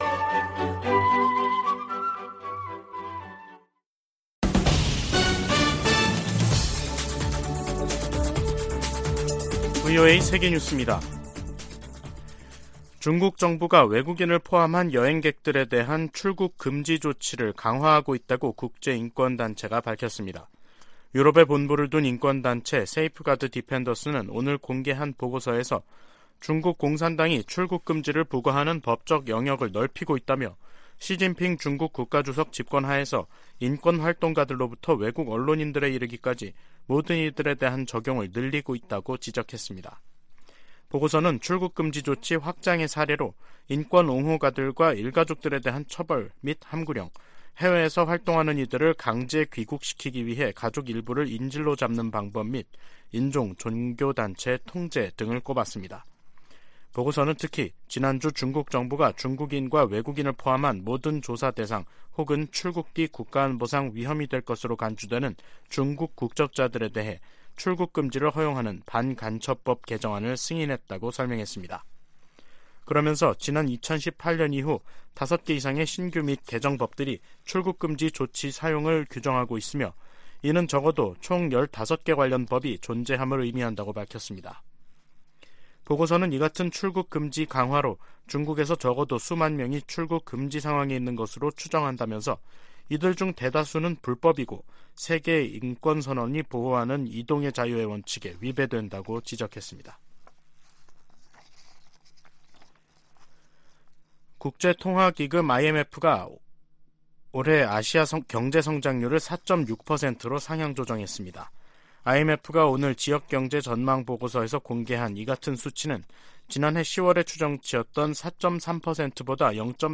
VOA 한국어 간판 뉴스 프로그램 '뉴스 투데이', 2023년 5월 2일 2부 방송입니다. 한일·미한일 정상회담이 이달 중 연이어 개최 될 예정입니다. 미 국무부는 워싱턴 선언은 북한의 핵 위협에 대한 미국의 억제력 강화 조치라며, 북한의 최근 비난을 일축했습니다. 미 국제종교자유위원회가 북한을 종교자유 특별우려국으로 재지정할 것을 권고했습니다.